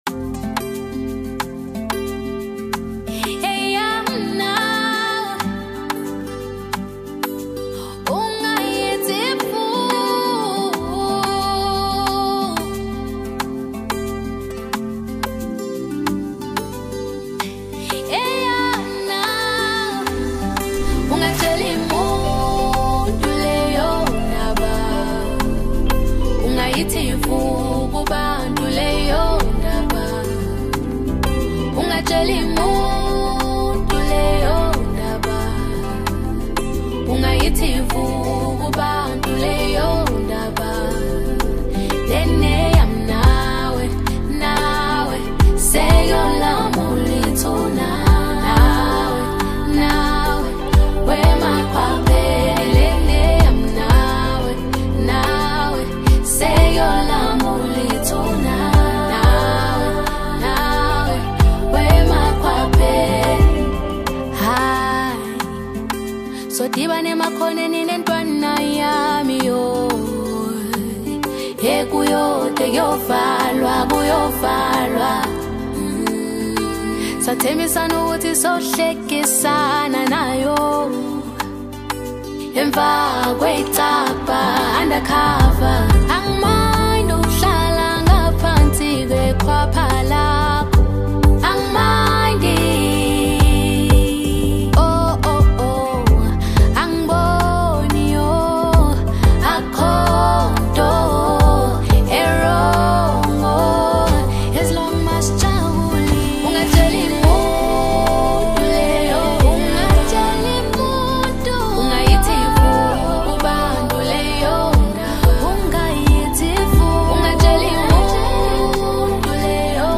AFRO-POP Apr 07, 2026